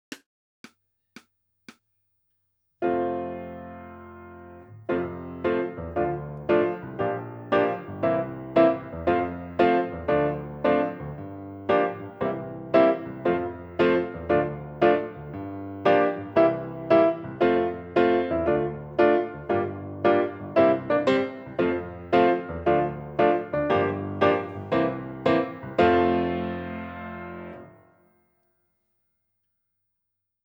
Audio 1.1: Piano begeleiding